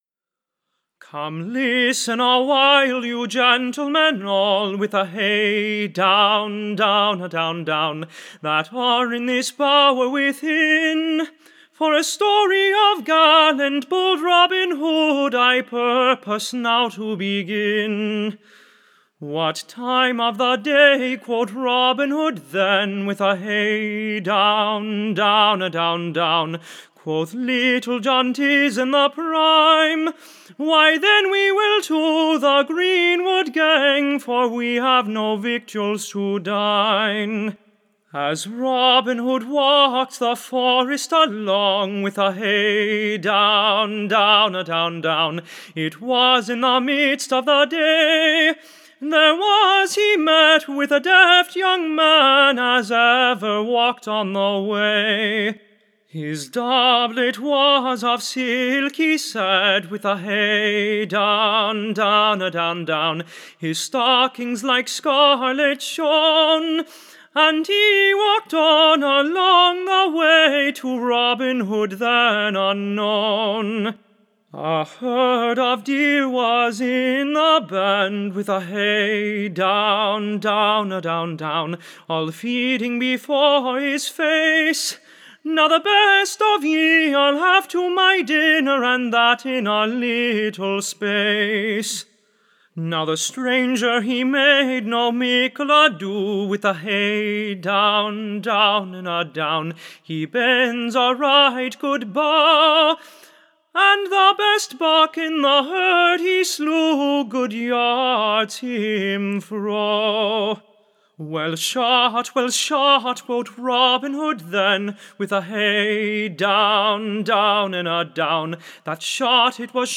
Recording Information Ballad Title Robin Hood newly Reviv'd: / OR, HIS / Meeting and Fighting with his Cousin SCARLET.